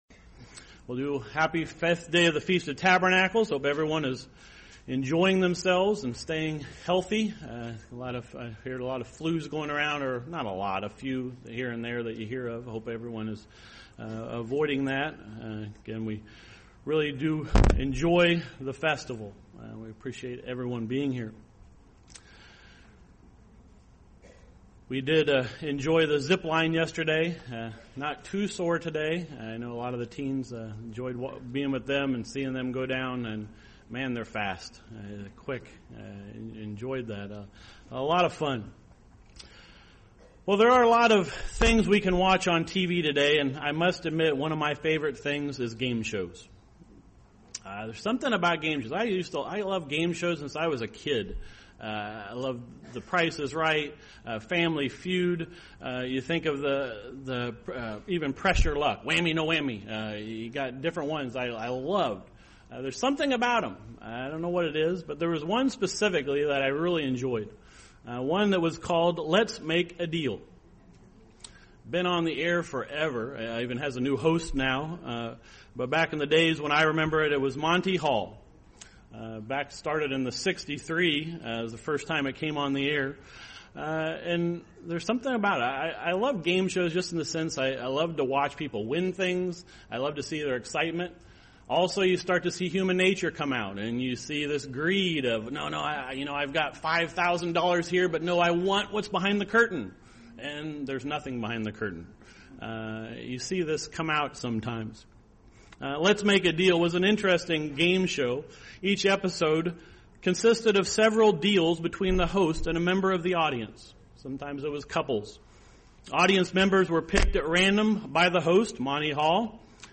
This sermon was given at the Lake George, New York 2012 Feast site.